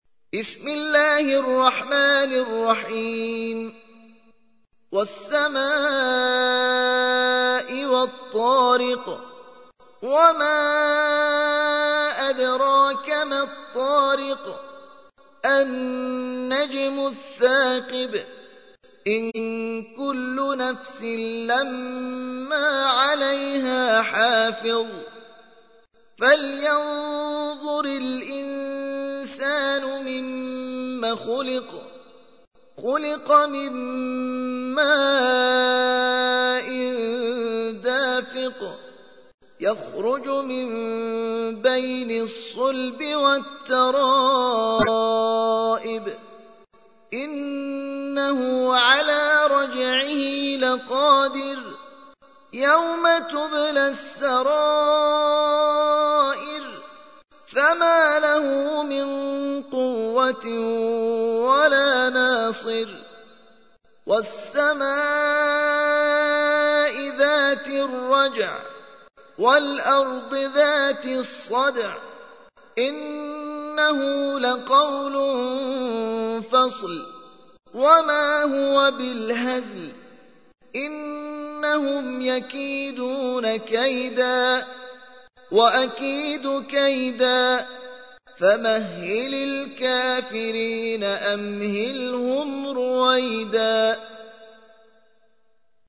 86. سورة الطارق / القارئ